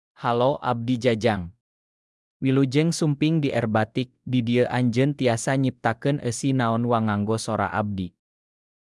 MaleSundanese (Indonesia)
JajangMale Sundanese AI voice
Jajang is a male AI voice for Sundanese (Indonesia).
Voice sample
Listen to Jajang's male Sundanese voice.
Jajang delivers clear pronunciation with authentic Indonesia Sundanese intonation, making your content sound professionally produced.